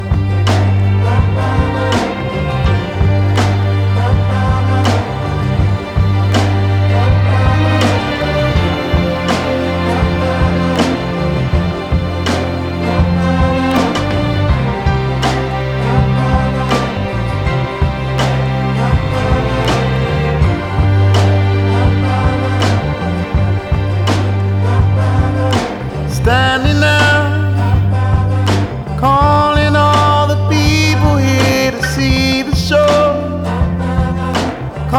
Жанр: Соул